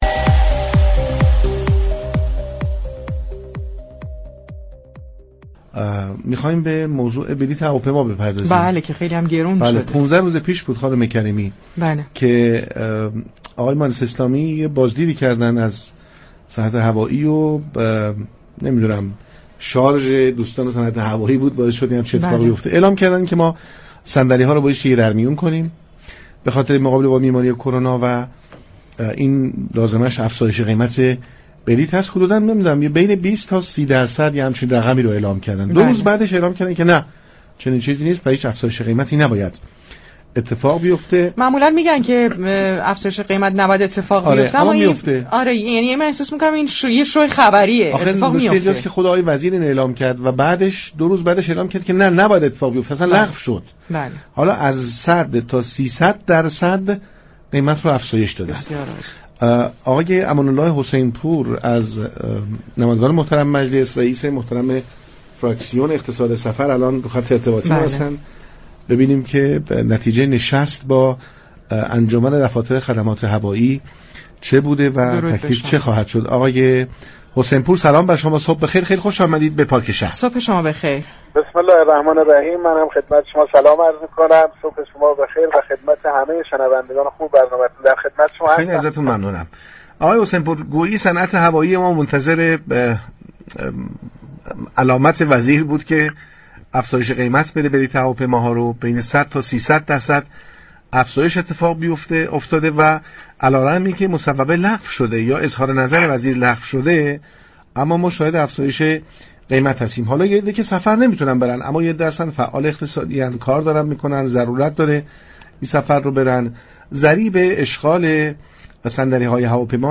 در همین راستا امان‌الله حسین پور نماینده مجلس شورای اسلامی و رئیس فراكسیون اقتصاد سفر با برنامه پارك شهر گفتگو كرد.